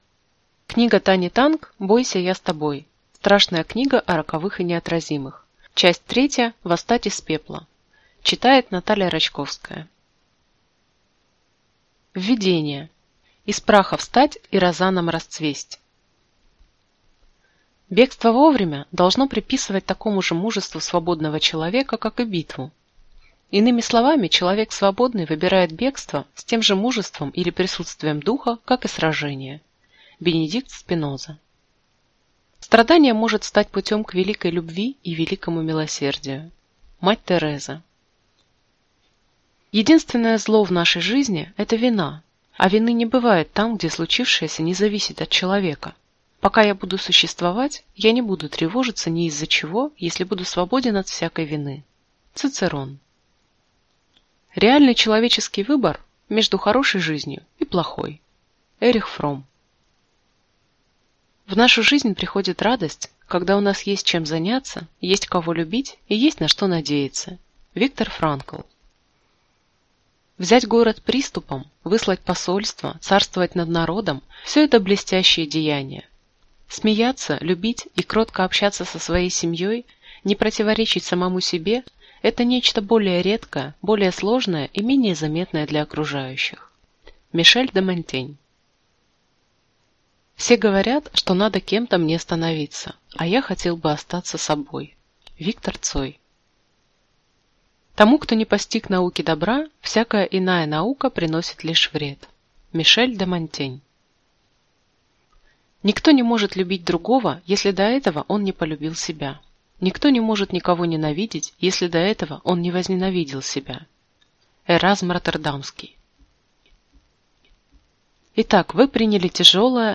Аудиокнига Бойся, я с тобой. Страшная книга о роковых и неотразимых. Часть 3: восстать из пепла | Библиотека аудиокниг